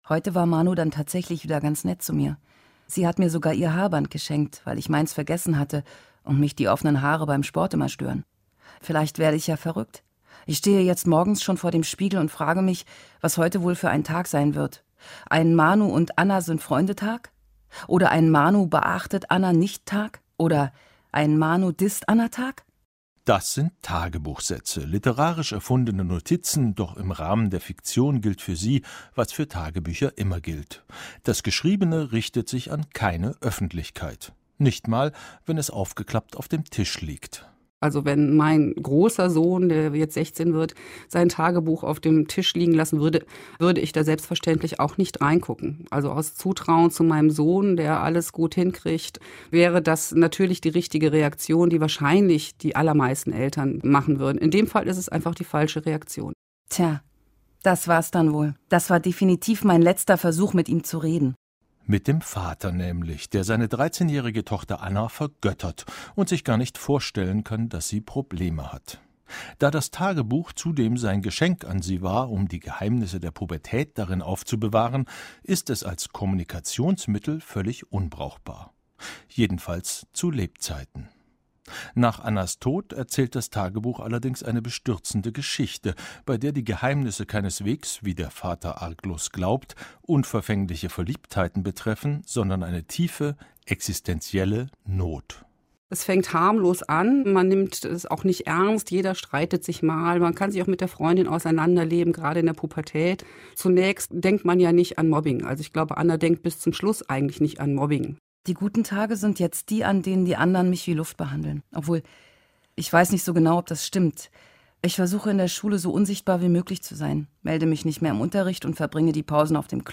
liest Kinderbücher